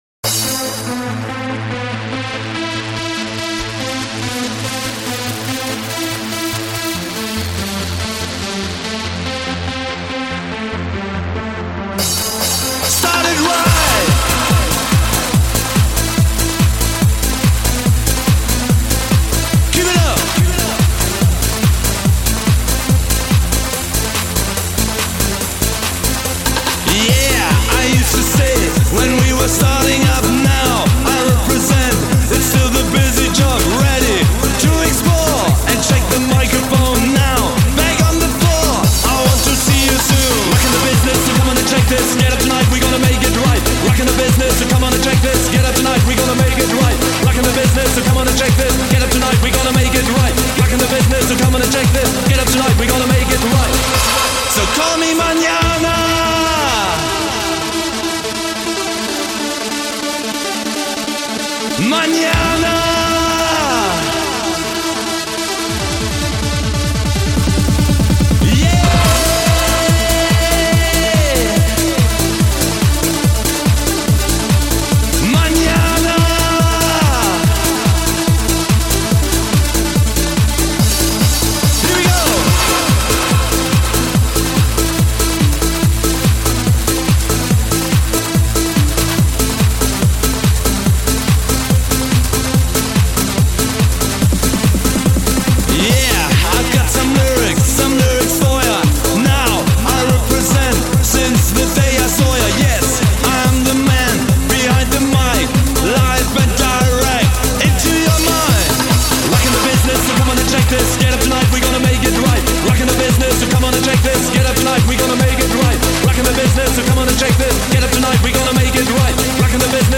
Жанр: Techno